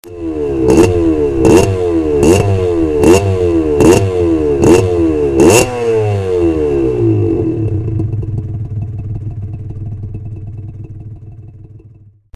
こんどのターゲットはバイクのエンジン音！
マイク（これだけもらった）を使った方がいい音で録音できることが判明。
Ｔ北大の駐輪場にて録音。普段の暖気運転よりも高めの回転数で（5000〜7000rpmくらい)回してます。